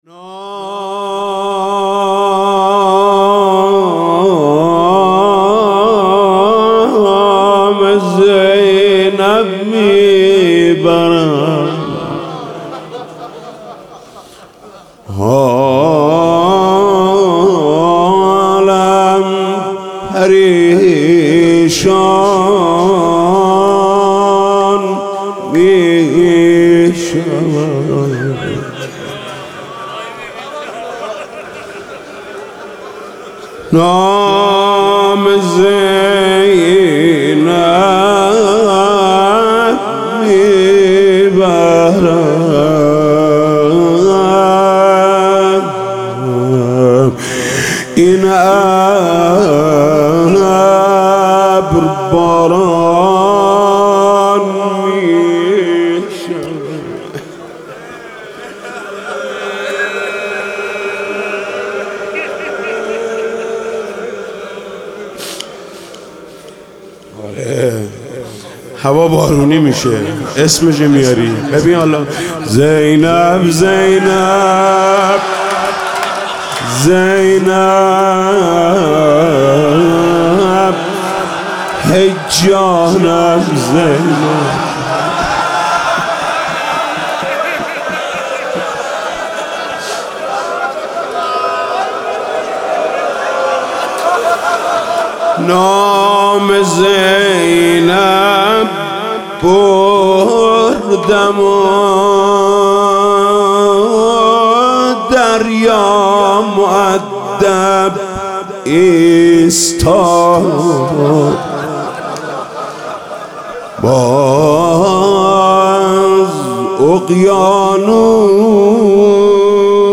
روضه: نام زینب می برم حالم پریشان می شود